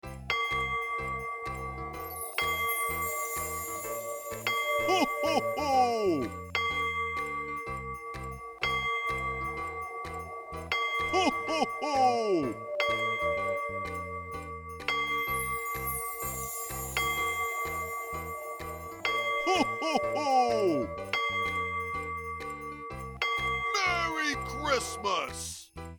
cuckoo-clock-12.wav